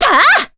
Veronica-Vox_Attack4_jp.wav